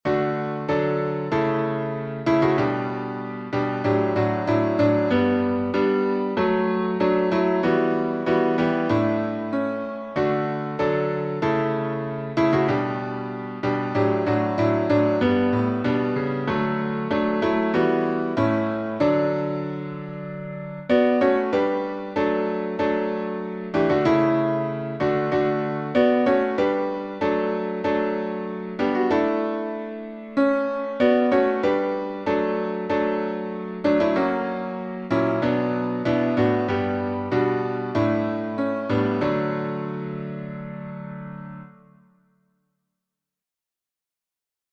Time signature: 4/4